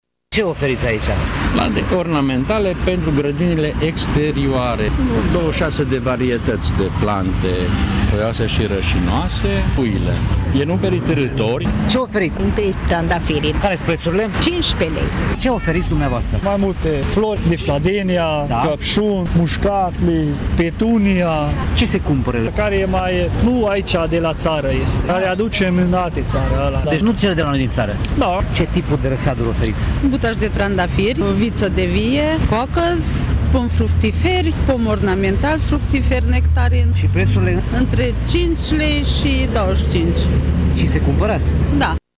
Unii au remarcat că sunt cumpătători care preferă florile din străinătate, în detrimental celor autohtone: